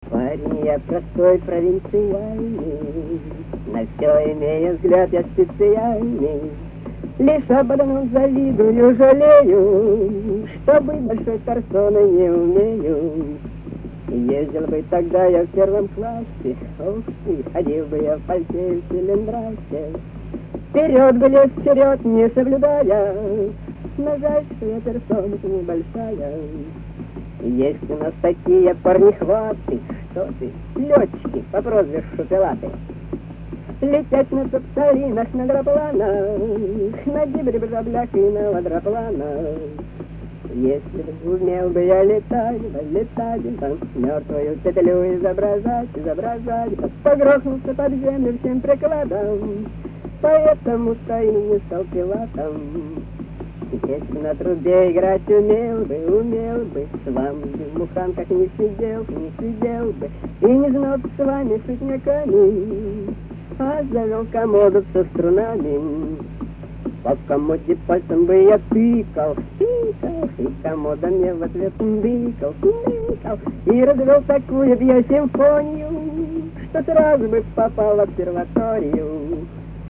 Качество невысокое.
Поют студенты 60-х.
poyut-studentyi-60-h---paren-ya-prostoy,-provintsialnyiy,-na-vso-imeyu-vzglyad-ya-spetsialnyiy....mp3